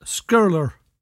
[skUHRLur]